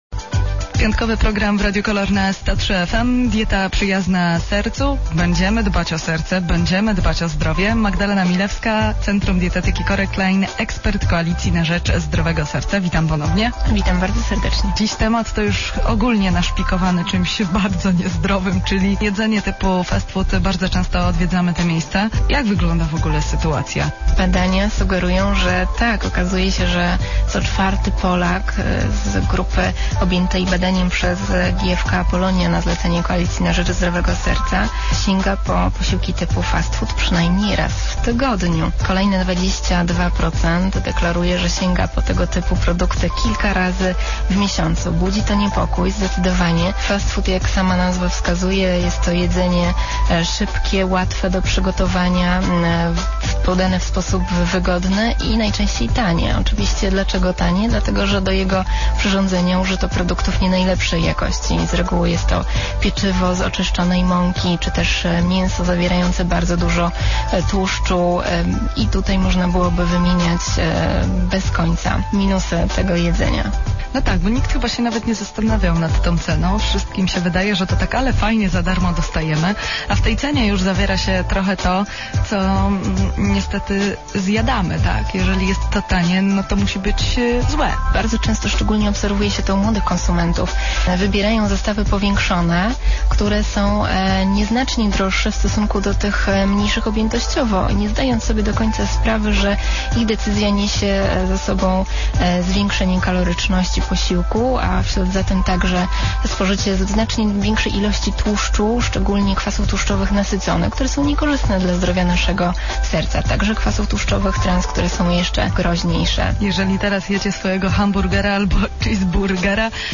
W ramach Kampanii rozpoczął się cykl audycji w radio KOLOR, w której radziliśmy zapracowanym Polakom jak zmienić nawyki żywieniowe na jak najbardziej korzystne dla zdrowia serca.